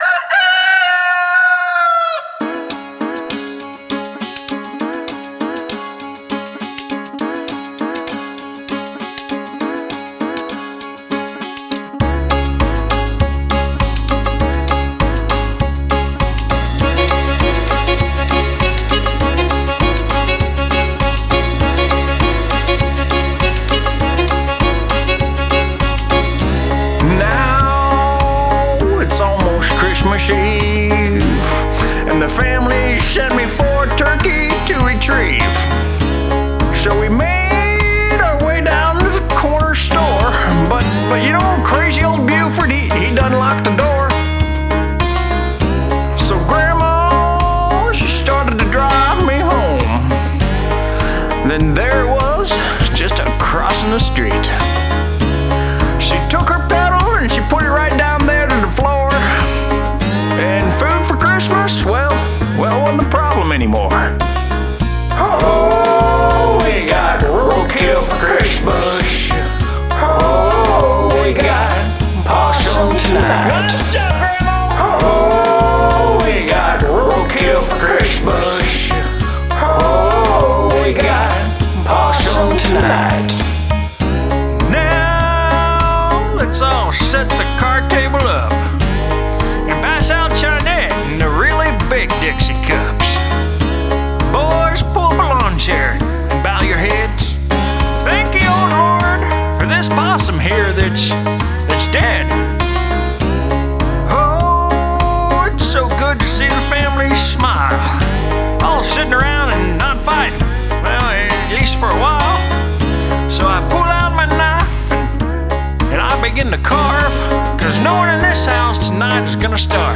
write one... and sing it!